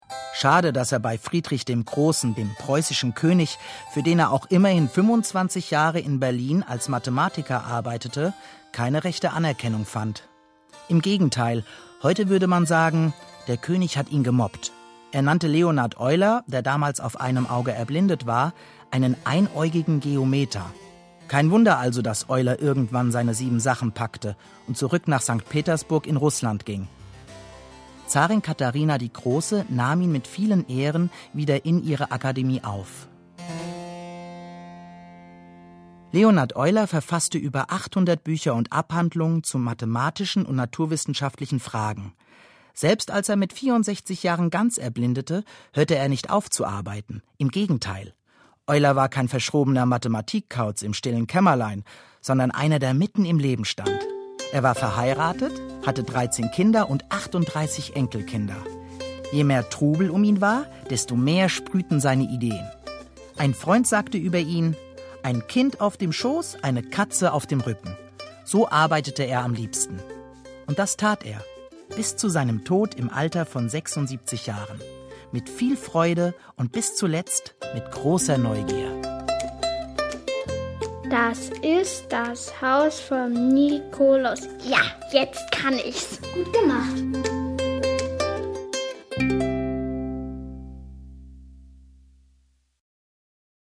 Meine Stimme klingt unverfälscht, jung und sympathisch und ist vielseitig einsetzbar für jugendliche bis männlich-zärtliche Charaktere.
Feature. „Leonhard Euler“ (Kinderfunkkolleg Mathematik)
Rolle: Erzähler
06-Feature-Leonard-Euler-Kinderfunkkolleg-Mathematik.mp3